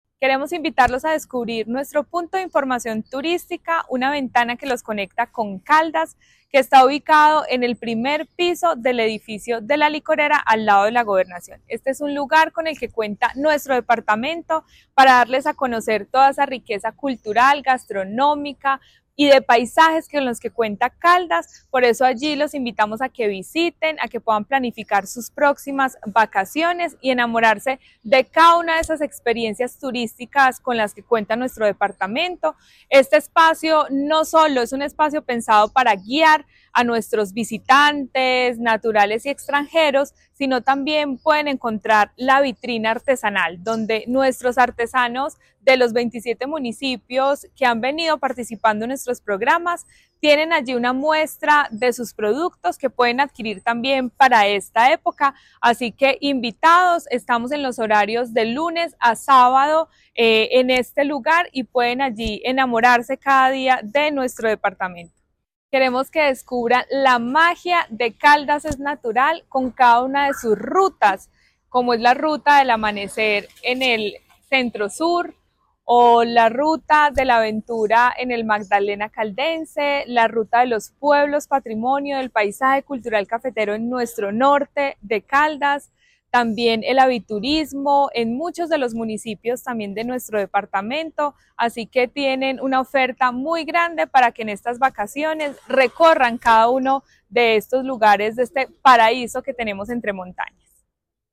Daissy Lorena Alzate, secretaria de Desarrollo, Empleo e Innovación de Caldas.